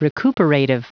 Prononciation du mot recuperative en anglais (fichier audio)